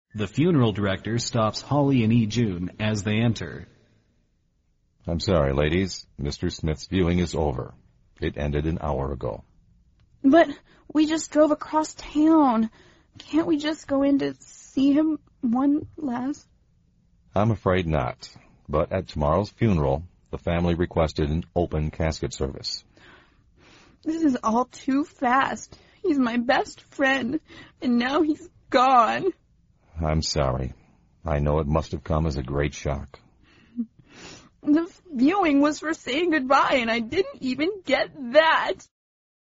美语会话实录第247期(MP3+文本):A great shock